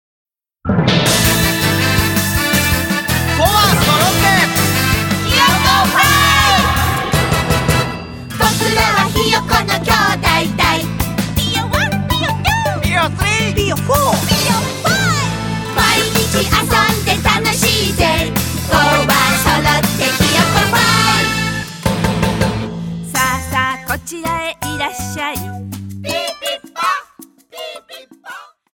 あそびうた